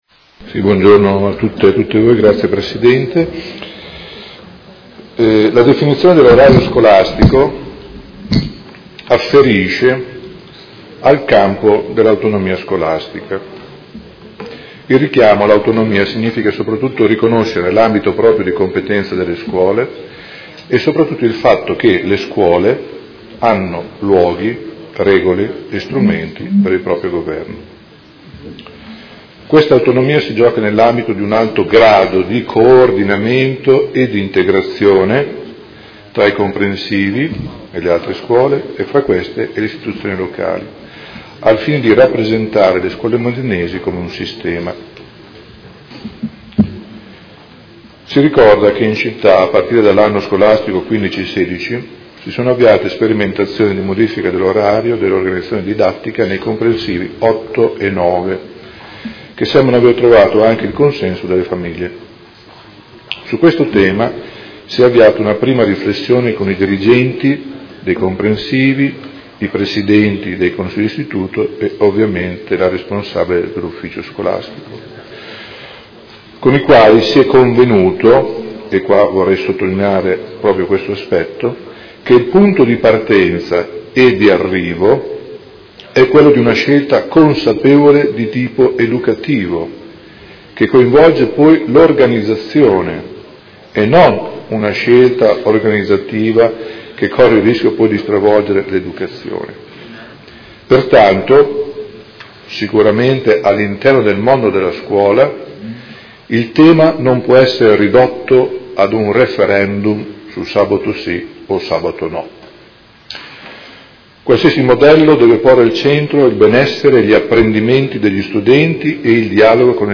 Seduta del 14/12/2017 Risponde. Interrogazione dei Consiglieri Baracchi e Carpentieri (PD) avente per oggetto: Organizzazione oraria scuole secondarie di primo grado